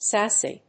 音節sass・y 発音記号・読み方
/sˈæsi(米国英語), ˈsæsi:(英国英語)/